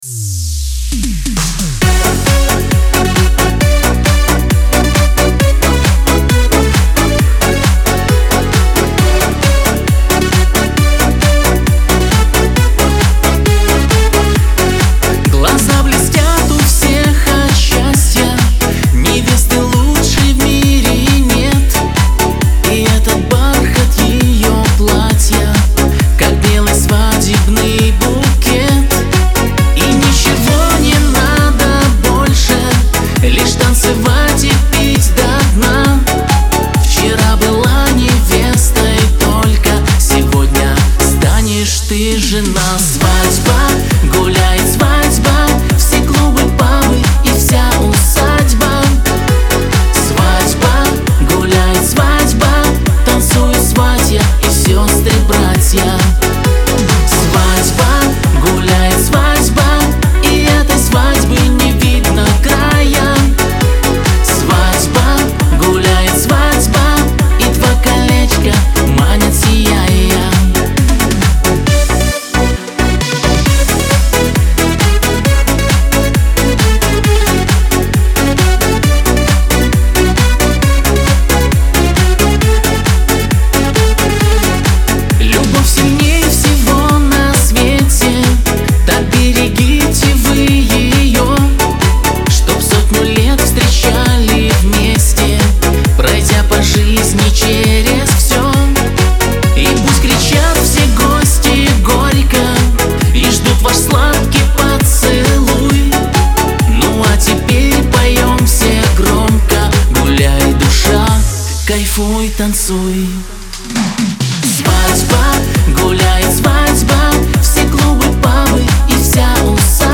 pop
диско
эстрада